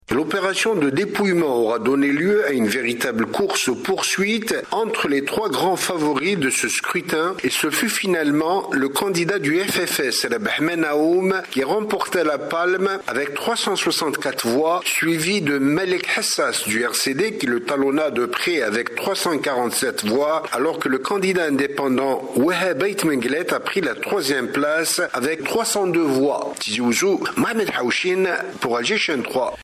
Le FFS reprend sa place à Tizi Ouzou, correspondance